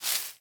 Minecraft Version Minecraft Version snapshot Latest Release | Latest Snapshot snapshot / assets / minecraft / sounds / block / cherry_leaves / step3.ogg Compare With Compare With Latest Release | Latest Snapshot
step3.ogg